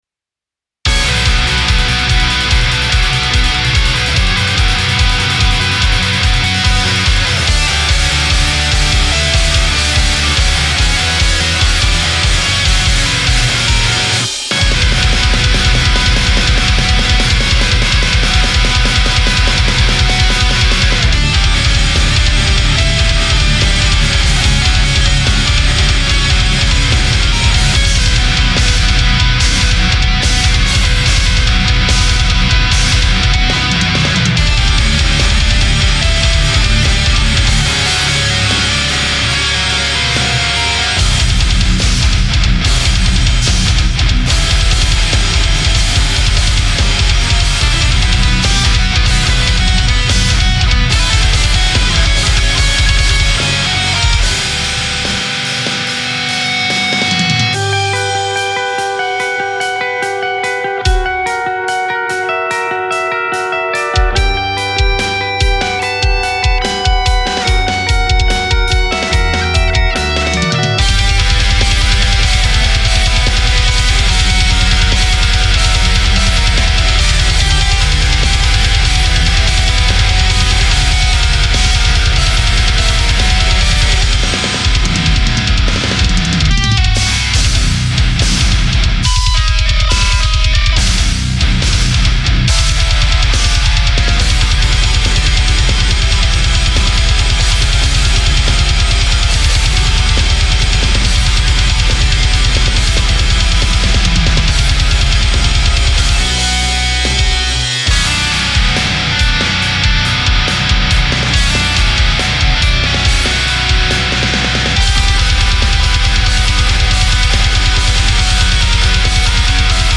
Critique my Metalcore mix
things i used: Line6 Pod Farm (line 6 studio gx) with Impulses SSD with drumagg trigger Piano1 3 guitar track 1 shitty bass tack because recorded wtih solo guitar mixed in cubase mastered in studio one how do you like it? what are the problems? help me to improve my home studio sounding!!!